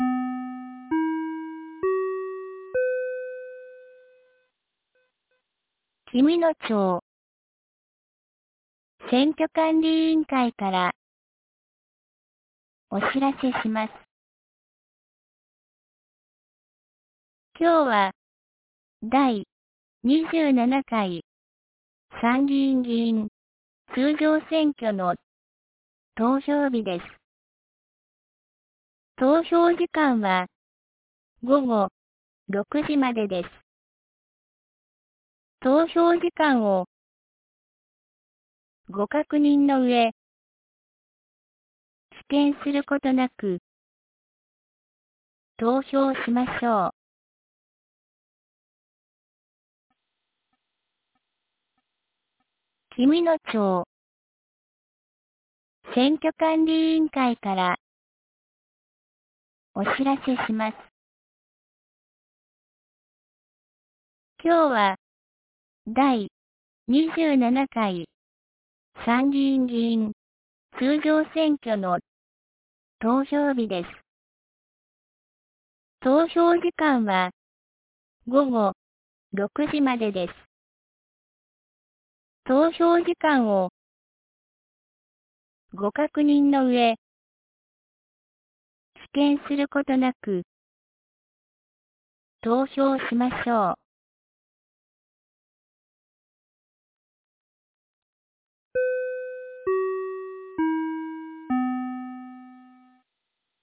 2025年07月20日 09時01分に、紀美野町より全地区へ放送がありました。